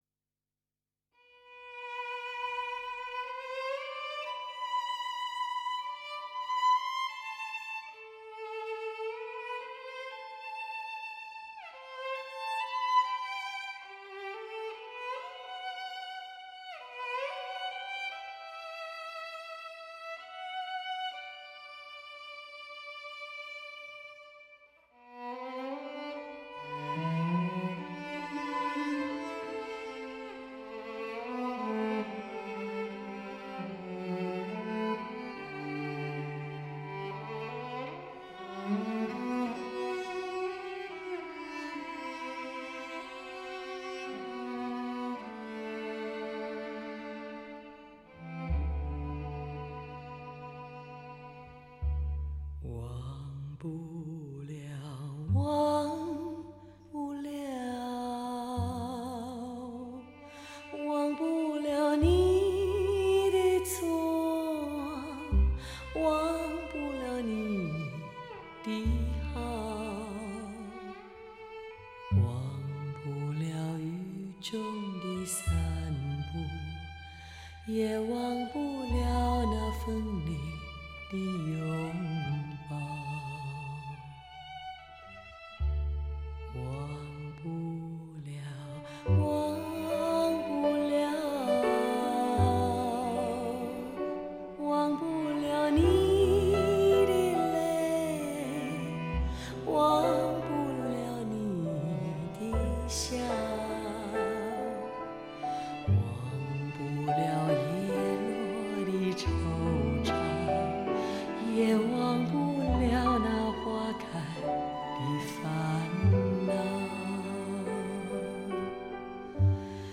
DTS-ES6.1